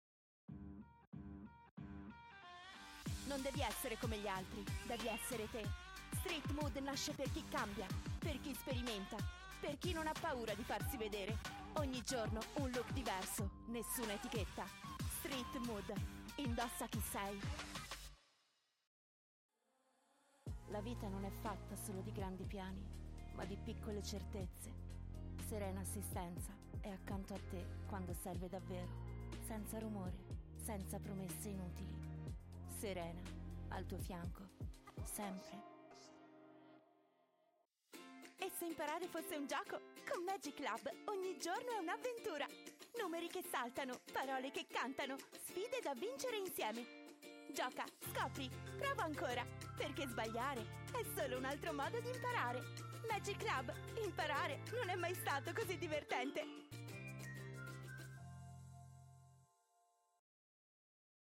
Young and lively voice, that can go from a soft sound, to a super enthusiastic tone.
Sprechprobe: Werbung (Muttersprache):
Neumann TLM 103 Focusrite Scarlett 2i2 4th gen ProTools
Commercials Reel.mp3